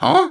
Super Mario Huuh